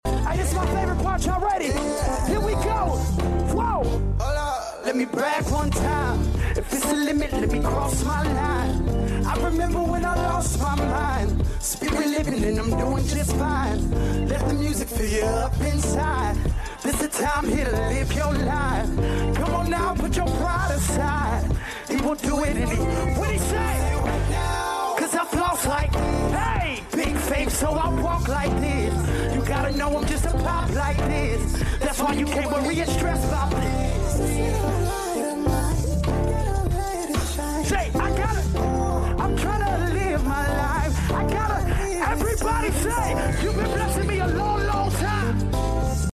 Lead Piano
Co-Lead Piano
Drummer